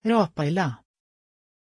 Pronunția numelui Raphaëlla
pronunciation-raphaëlla-sv.mp3